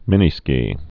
(mĭnē-skē)